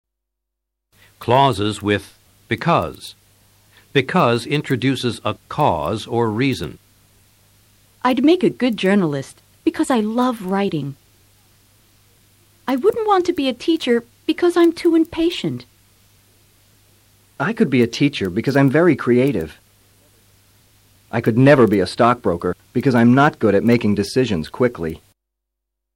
Escucha a los profesores y presta atención al uso de BECAUSE en estas oraciones.